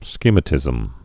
(skēmə-tĭzəm)